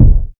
KICK.135.NEPT.wav